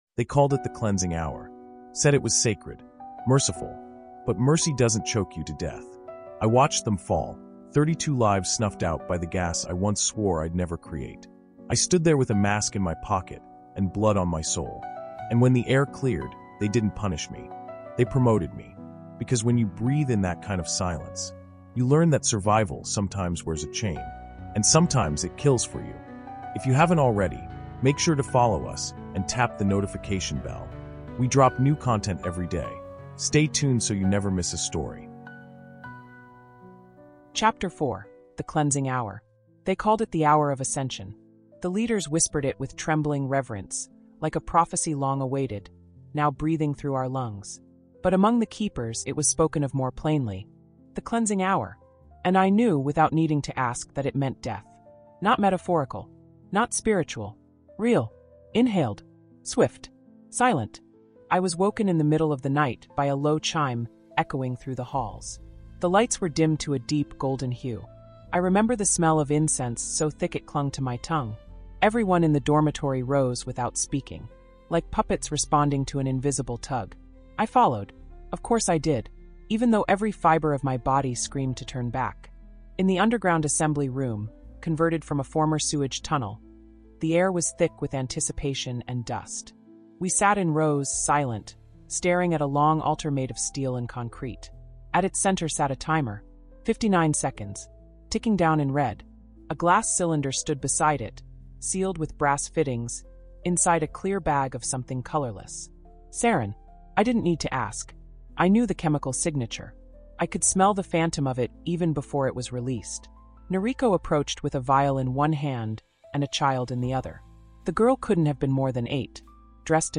Terror in Tokyo Subway Chapter Four | The Cleansing Hour | Audiobook